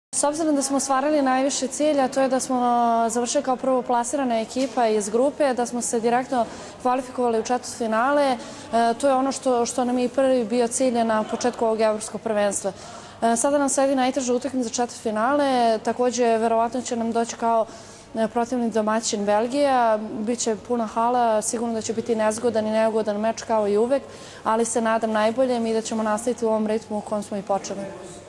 IZJAVA ANE BJELICE